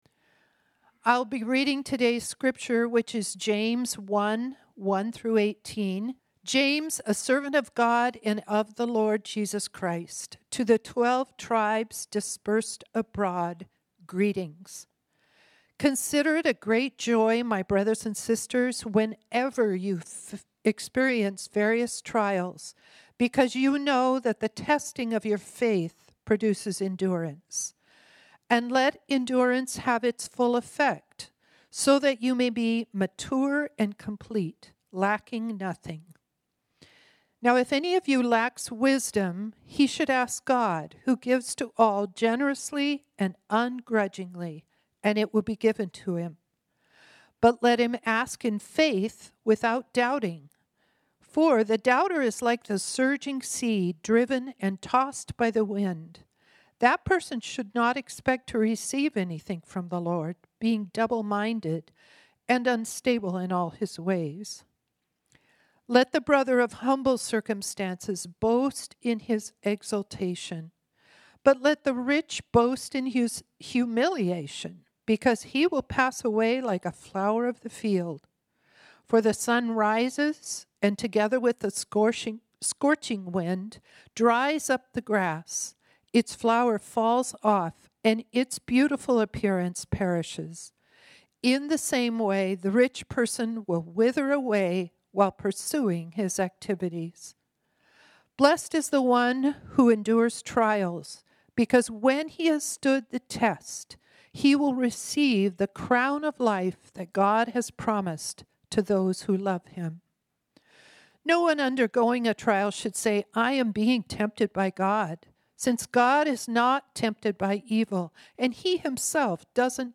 This sermon was originally preached on Sunday, October 5, 2025.